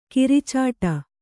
♪ kiricāṭa